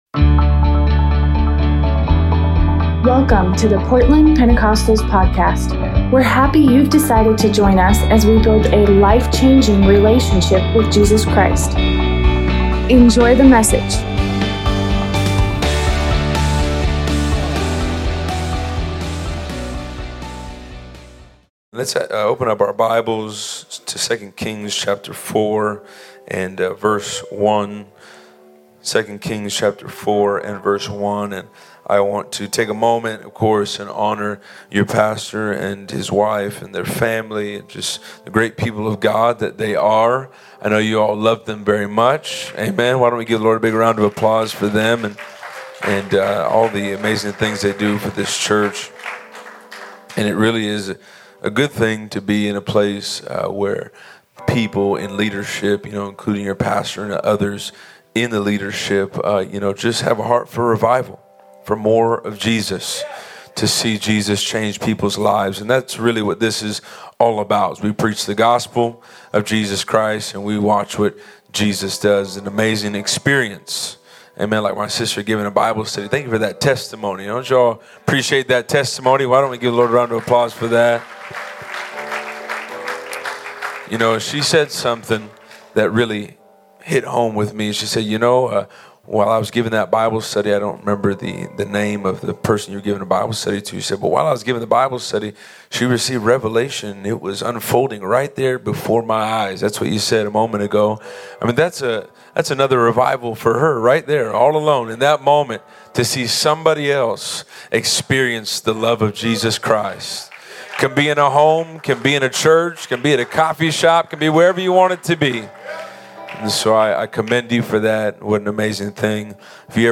Sunday revival service